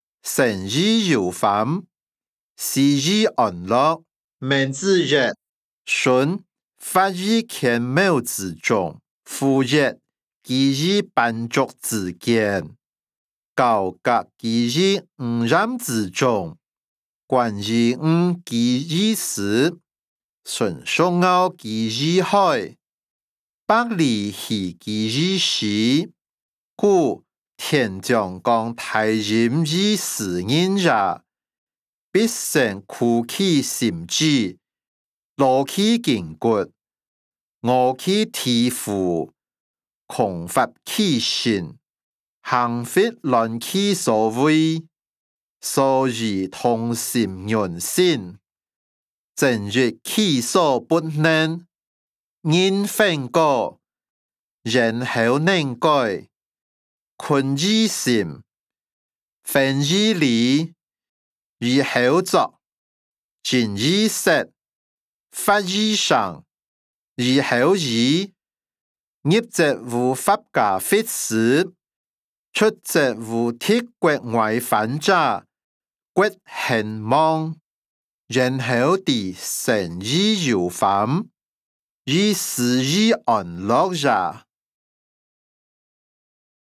經學、論孟-生於憂患，死於安樂音檔(饒平腔)